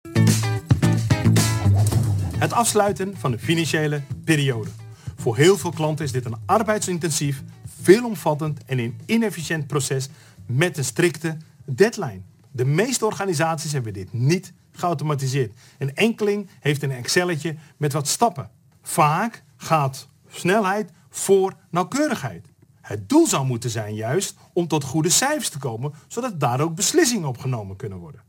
Podcast opgenomen tijdens Succestival 2023.